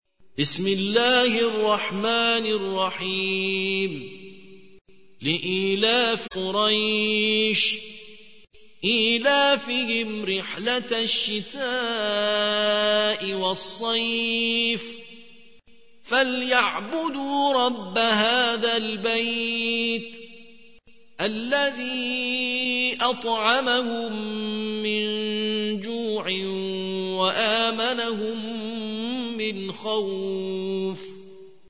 ترتیل سوره قریش